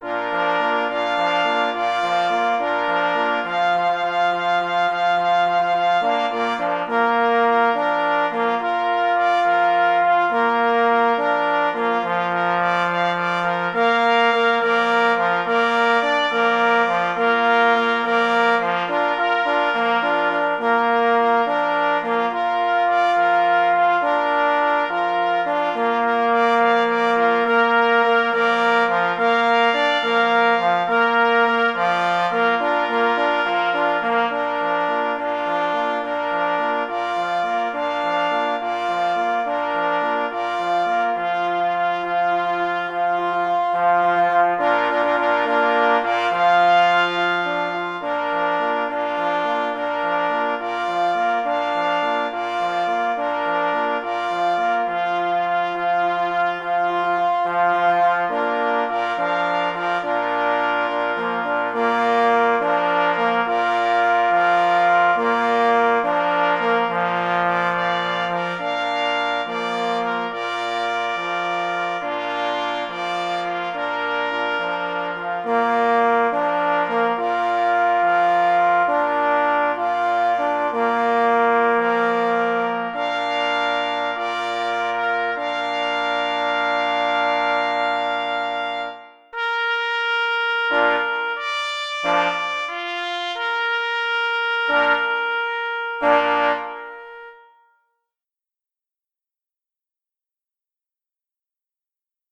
Humoreska „Po polowaniu na zające” powstała w lutym 2022 roku. W początkowych taktach przewija się fragment sygnału „Zając na rozkładzie”.